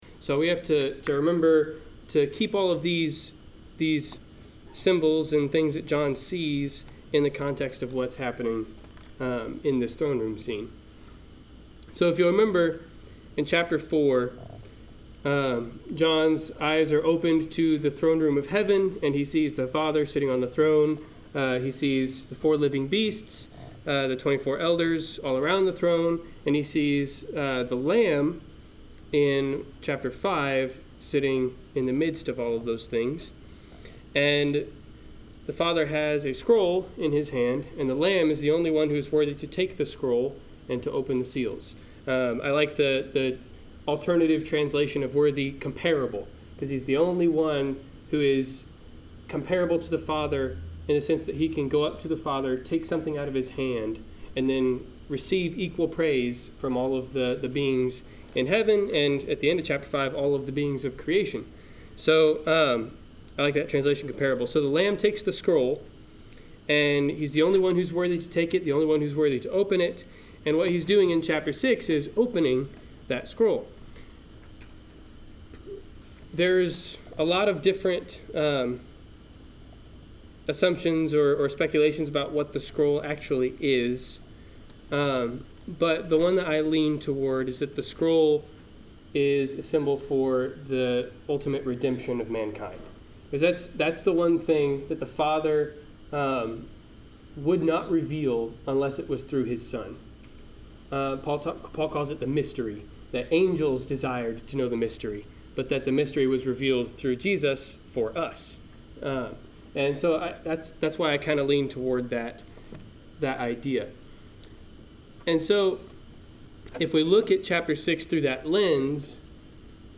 Passage: Revelation 6-7 Service Type: Wednesday Night Class